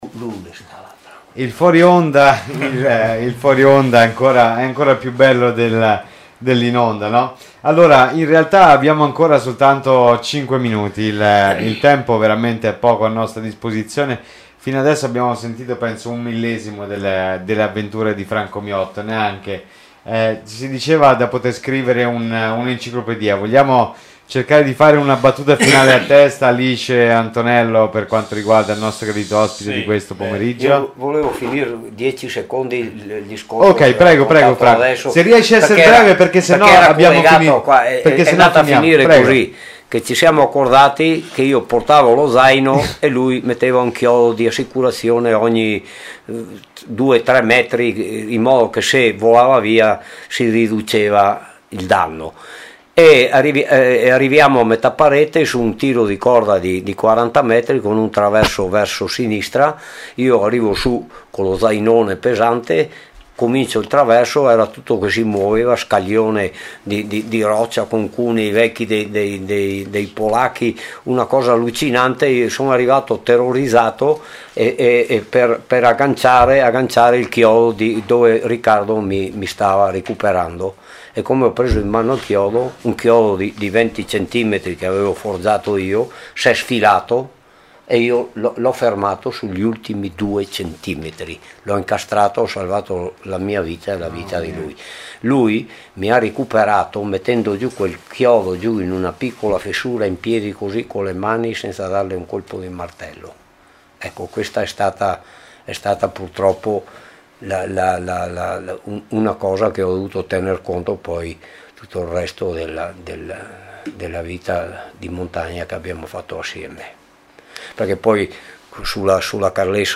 88 ANNI UNA VITA PER LA MONTAGNA. l’intervista ricordo alla radio